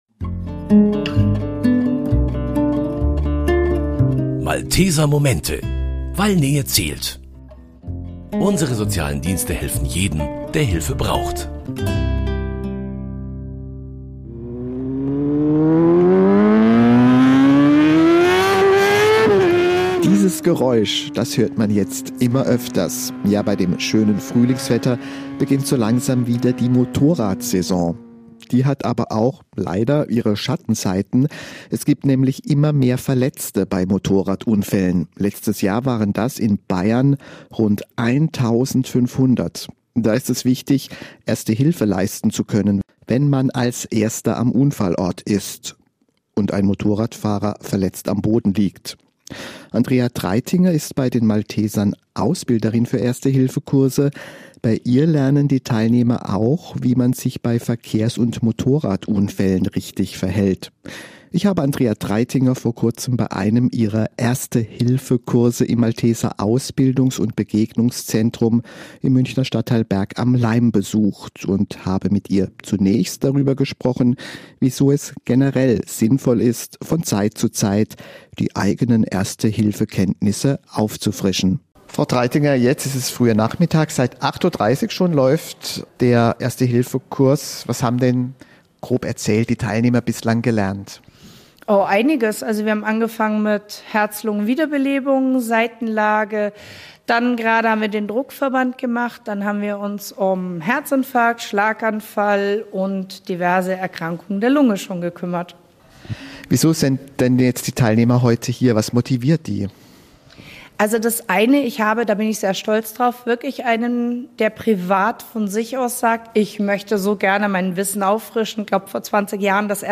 Im Malteser Erste-Hilfe-Kurs ist der Motorrad-Unfall fester Bestandteil. Wir haben in München einen Kurs besucht